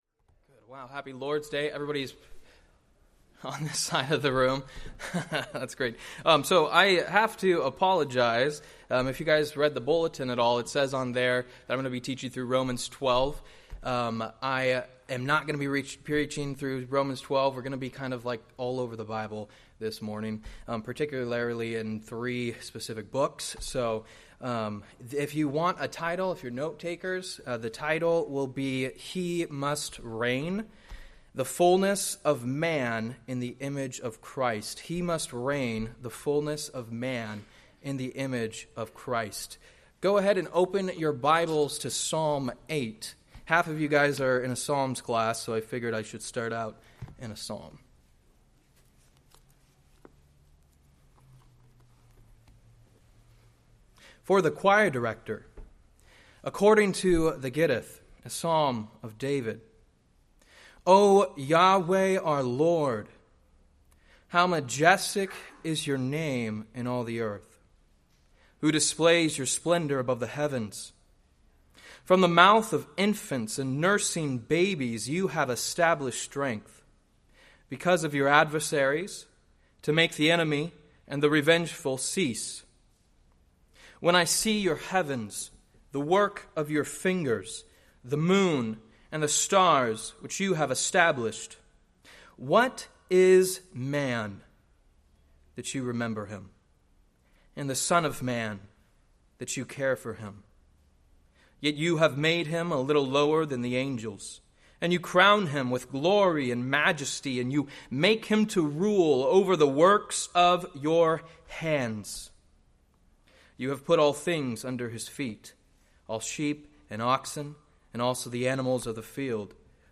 Date: Jul 21, 2024 Series: Various Sunday School Grouping: Sunday School (Adult) More: Download MP3